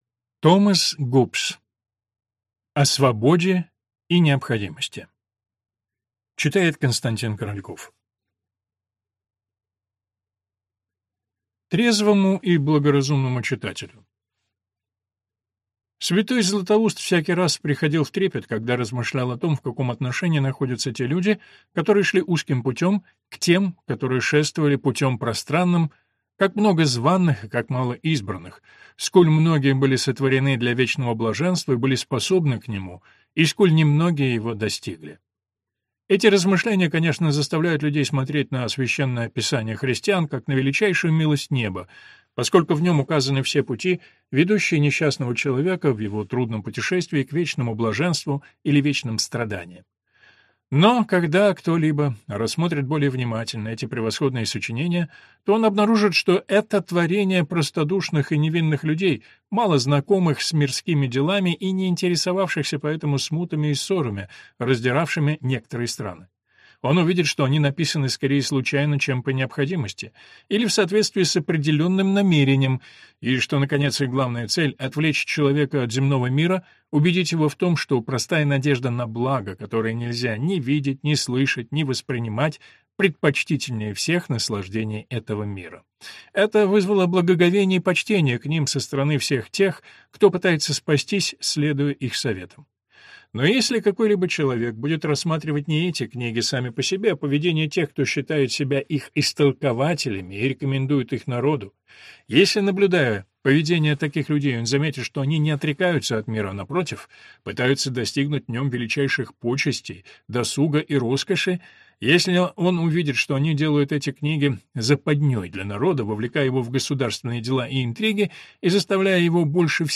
Аудиокнига О свободе и необходимости | Библиотека аудиокниг